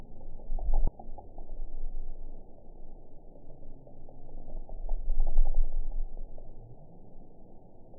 event 922106 date 12/26/24 time 12:06:39 GMT (5 months, 3 weeks ago) score 5.56 location TSS-AB06 detected by nrw target species NRW annotations +NRW Spectrogram: Frequency (kHz) vs. Time (s) audio not available .wav